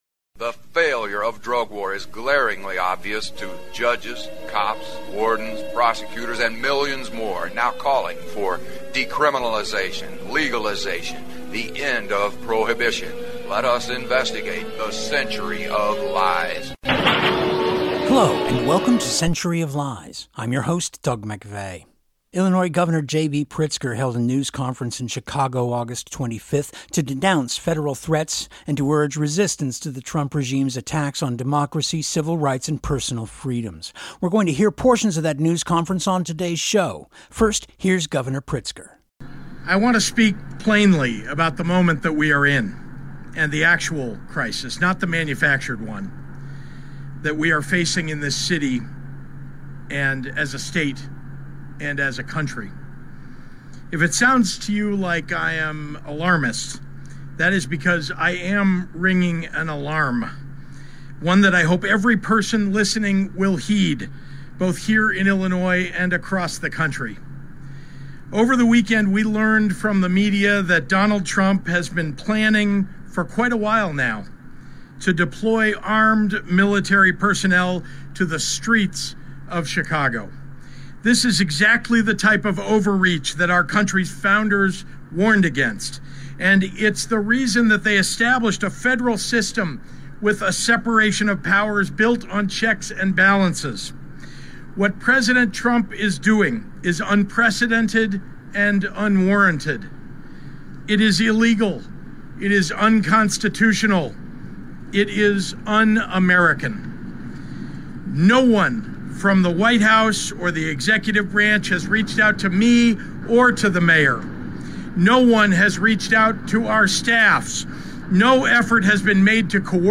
Illinois Governor JB Pritzker held a news conference in Chicago August 25 to denounce federal threats and urge to resistance to the Trump regime’s attacks on democracy, civil rights, and personal freedoms.